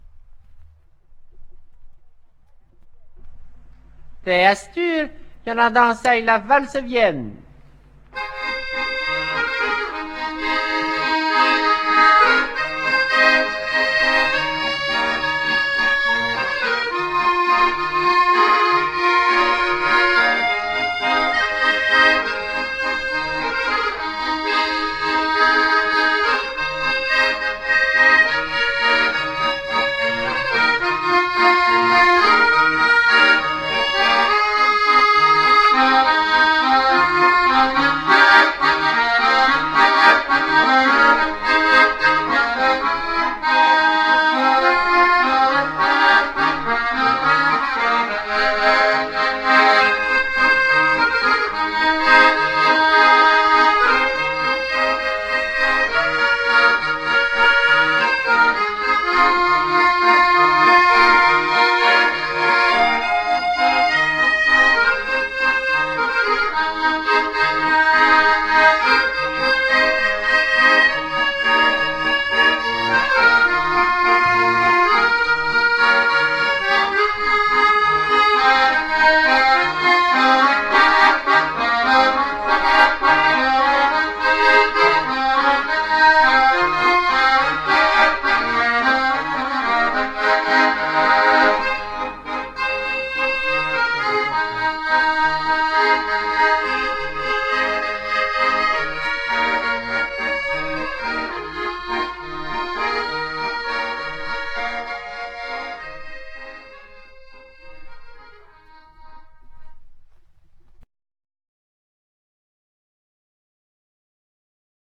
(valse)